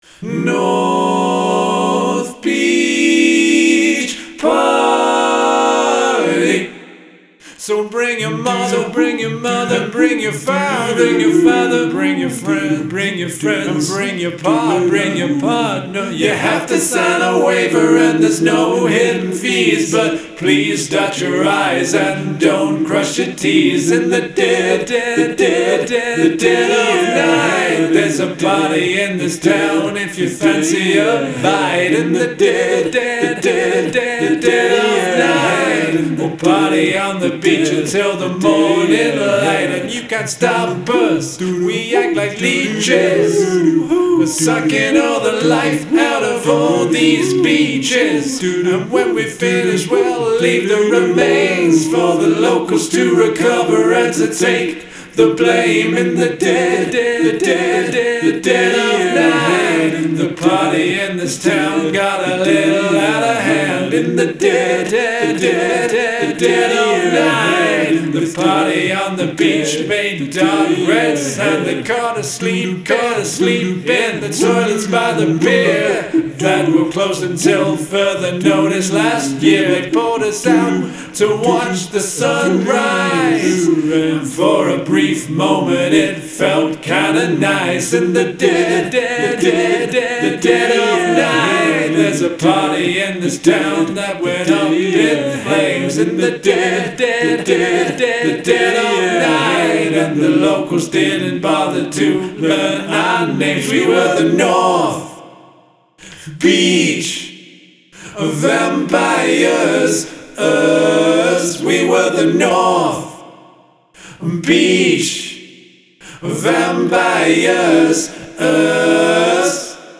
Include an a cappella section
Your intended chord structure doesn't come across clearly.